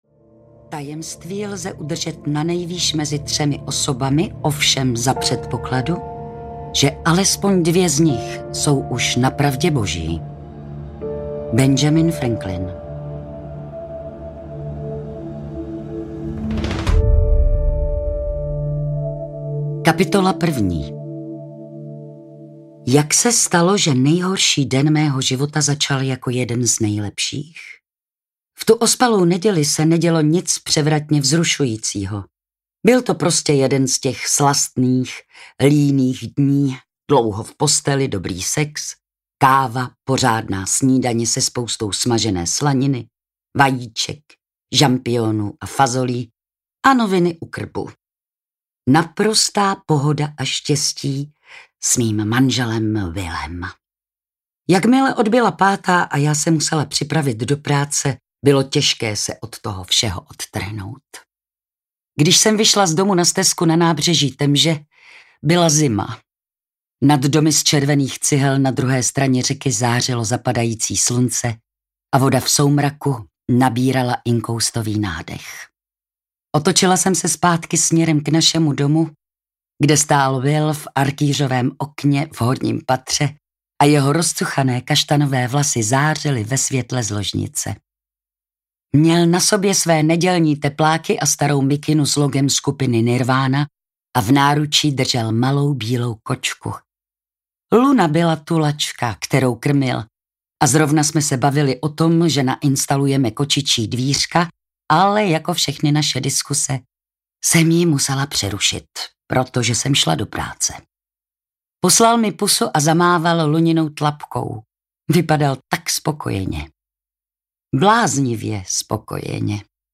Tíživé ticho audiokniha
Ukázka z knihy
• InterpretVilma Cibulková